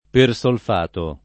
[ per S olf # to ]